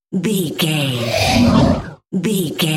Whoosh creature growl fast
Sound Effects
Atonal
Fast
ominous
dark
eerie